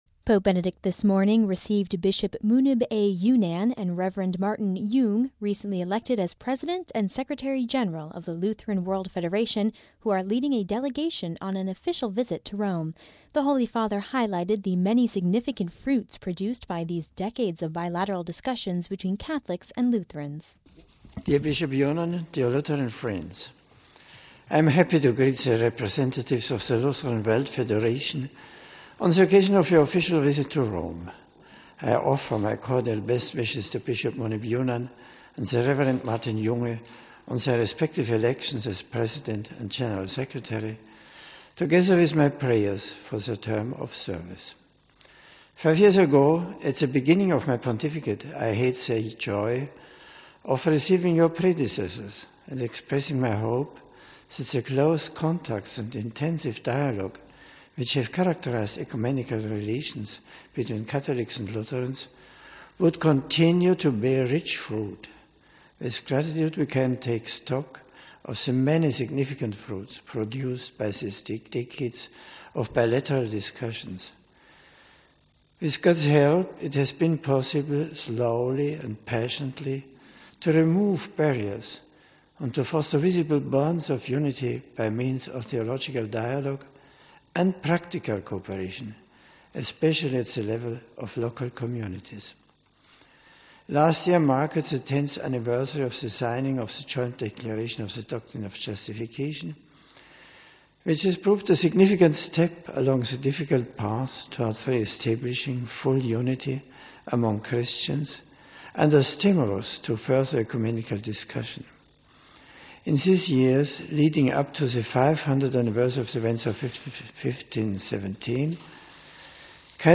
Beginning his English-language address to the group, the Pope highlighted "the many significant fruits produced by these decades of bilateral discussions" between Catholics and Lutherans.